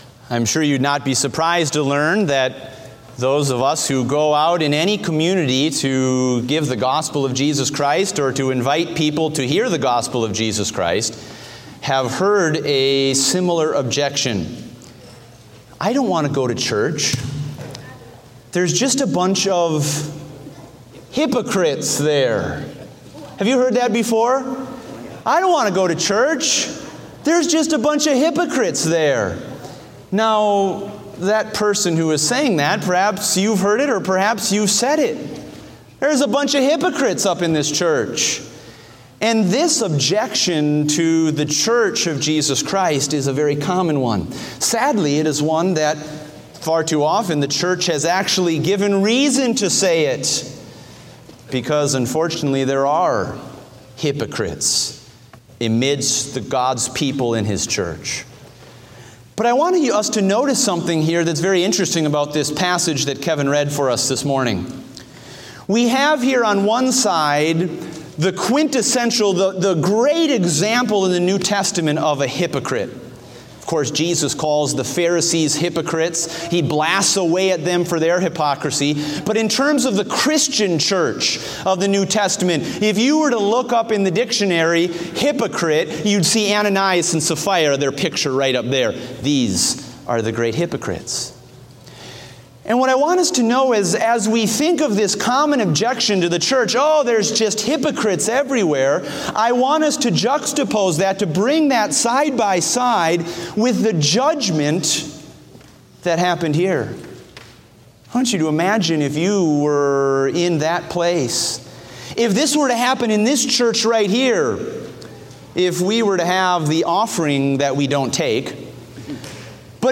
Date: August 7, 2016 (Morning Service)